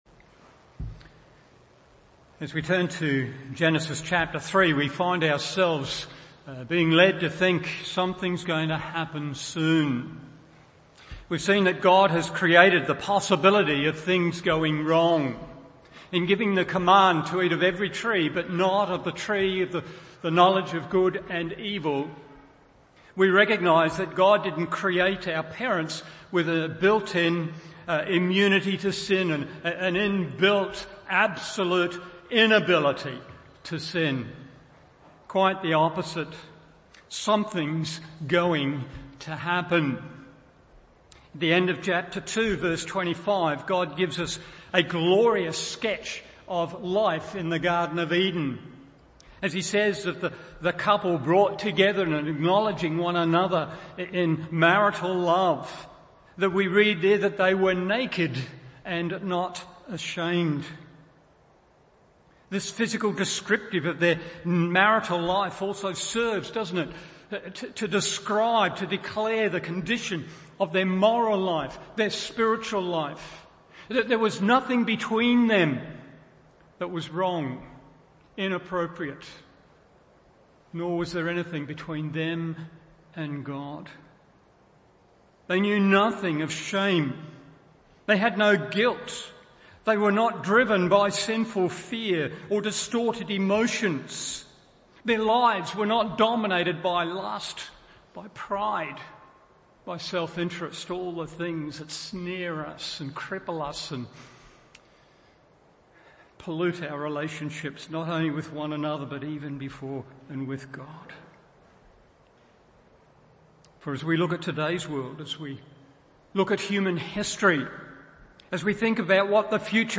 Morning Service Genesis 3:1-7 1.